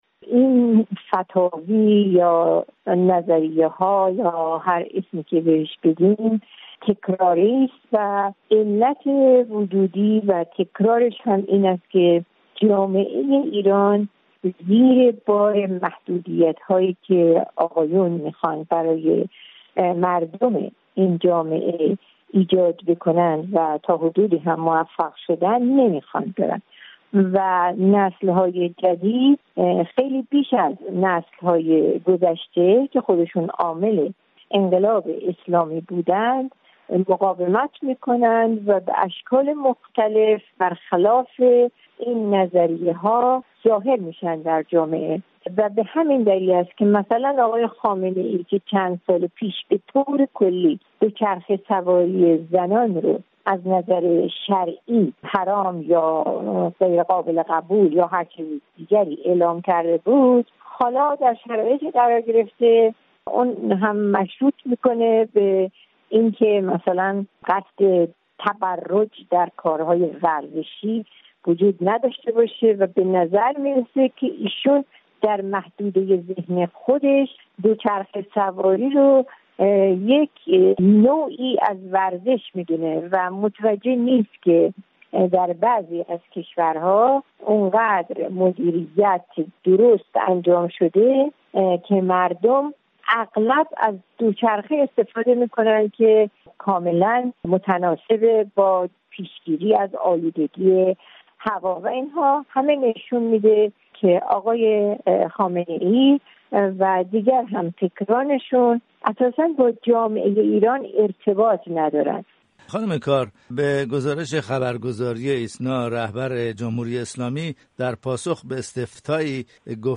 گفت‌وگو بت مهرانگیز کار، حقوقدان و فعال مدنی در واشینگتن: